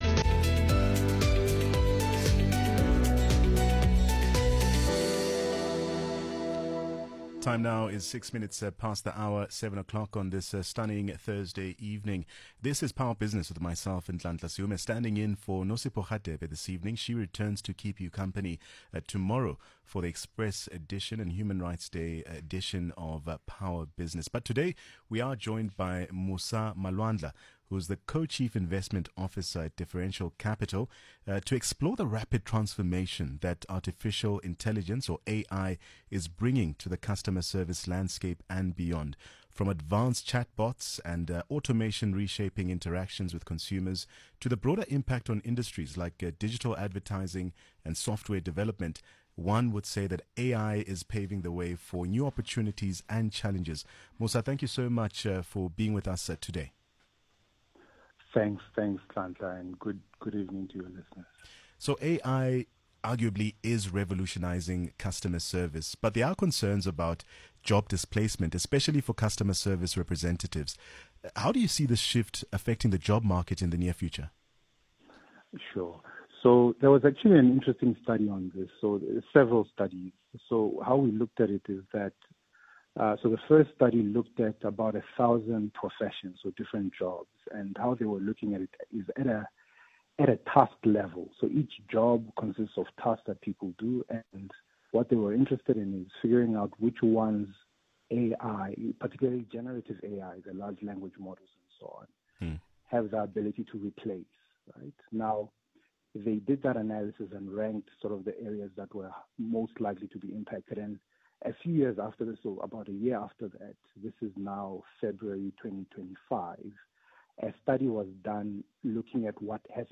Power-FM-Interview.mp3